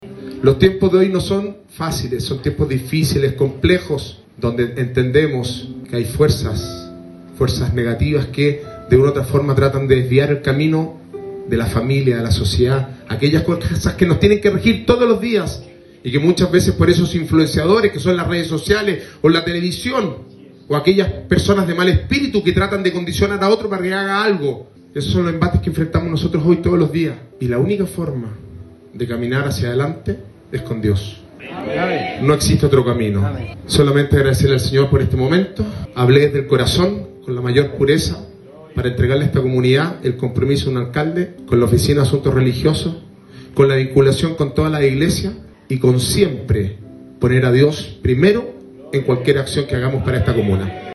El alcalde Sebastián Álvarez subrayó que “esos son los embates que enfrentamos hoy todos los días y la única forma de caminar hacia adelante es con Dios. No existe otro camino. Solamente agradecerle al Señor por este momento”, rubricó con tono emocionado el edil puconino, quien reconoció que, en este entorno de espiritualidad y reconocimiento por la Patria, “hablé desde el corazón con la mayor pureza para entregarle a esta comunidad el compromiso de un alcalde con la Oficina de Asuntos Religiosos”.
Alcalde-Sebastian-Alvarez-en-Te-Deum-evangelico-.mp3